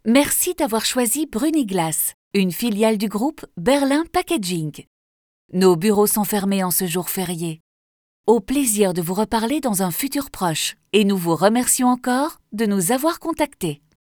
Kommerziell, Verspielt, Freundlich, Vielseitig, Sanft
Telefonie